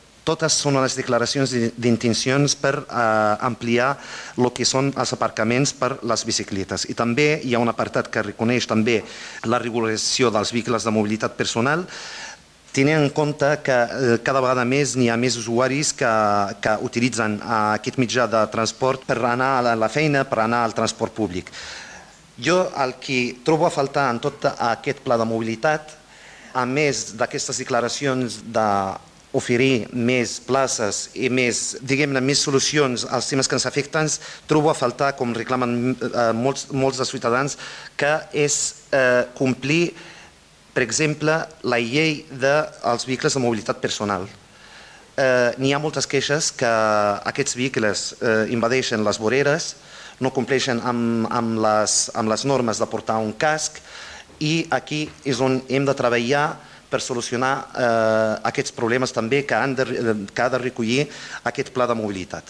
PLe Municipal. Juliol de 2025
Soulimane Messaoudi, regidor ERC